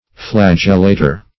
Search Result for " flagellator" : The Collaborative International Dictionary of English v.0.48: Flagellator \Flag"el*la`tor\, n. One who practices flagellation; one who whips or scourges.